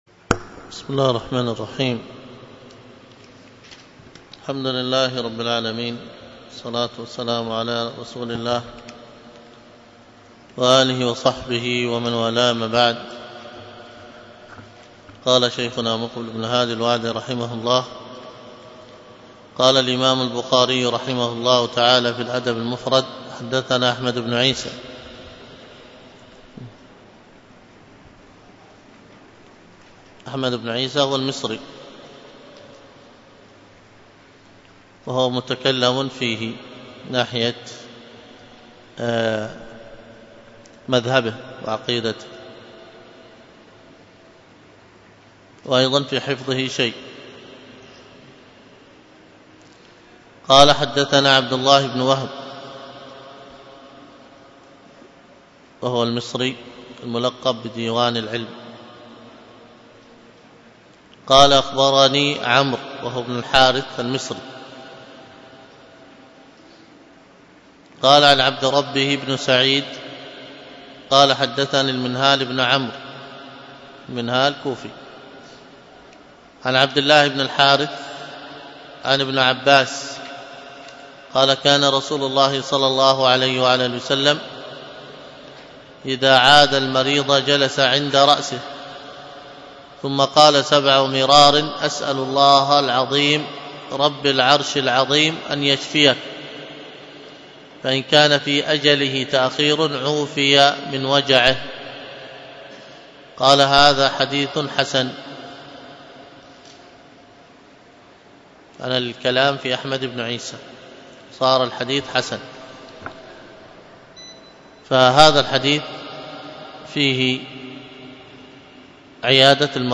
الدرس في التبيان في آداب حملة القرآن 65، ألقاها